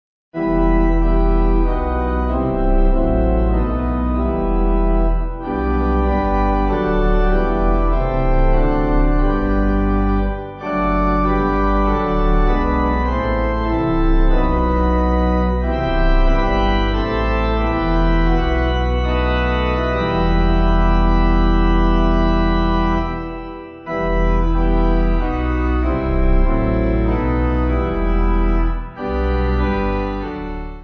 (CM)   3/Em